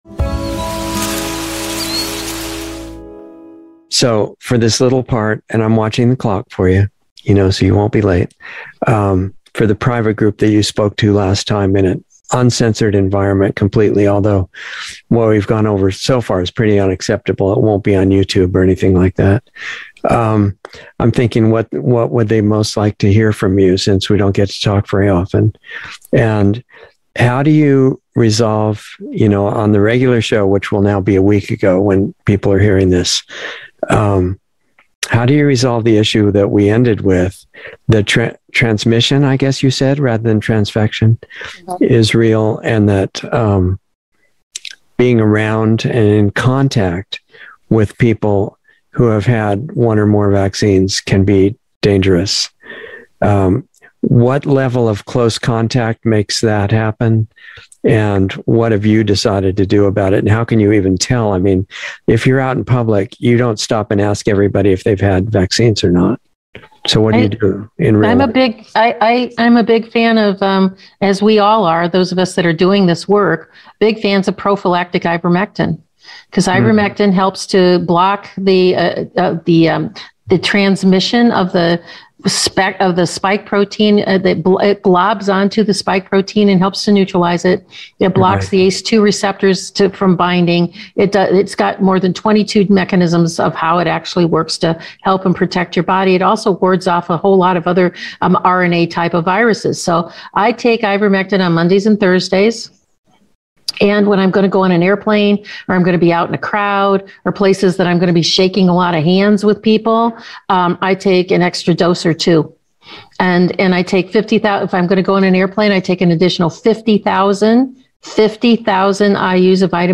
Planetary Healing Club - Dr. Sherri Tenpenny - Insider Interview 2/8/22